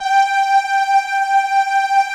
Index of /90_sSampleCDs/Club-50 - Foundations Roland/VOX_xScats_Choir/VOX_xSyn Choir 1